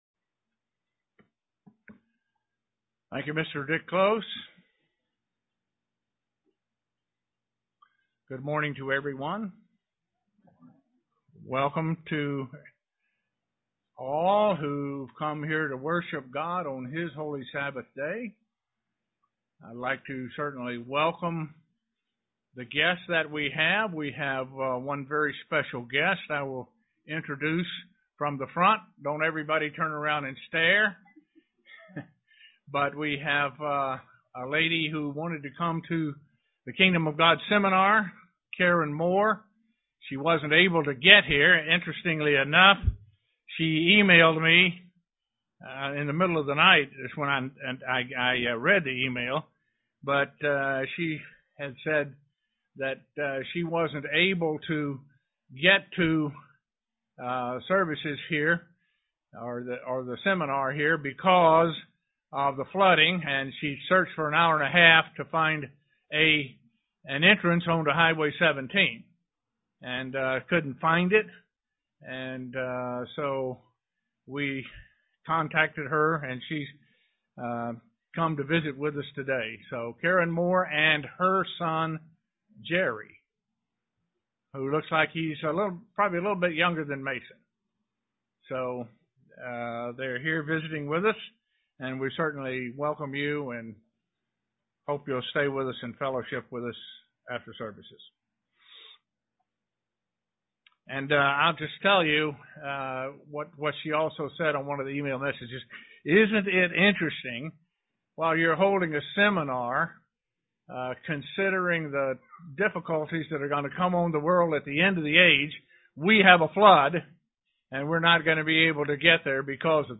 Given in Elmira, NY
Print Why it is important to God that we worship on the Sabbath, the day He commanded UCG Sermon Studying the bible?